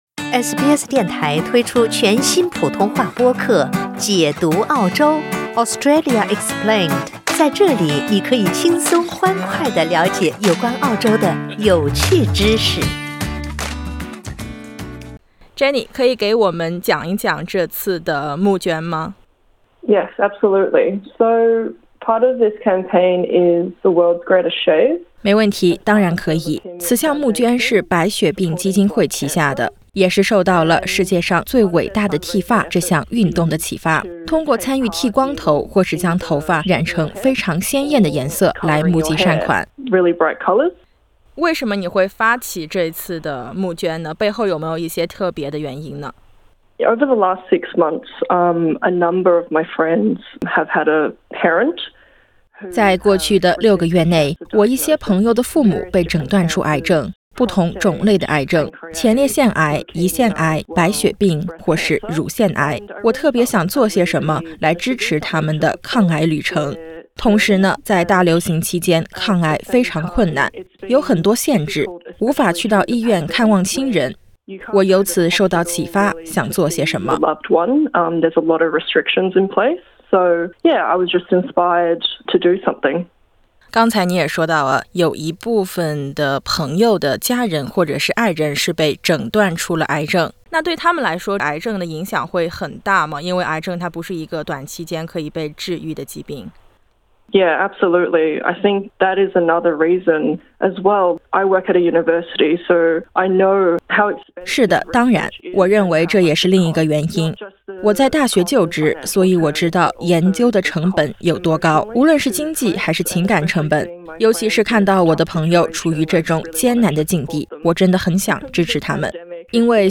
請聽SBS普通話記者為您帶來的寀訪。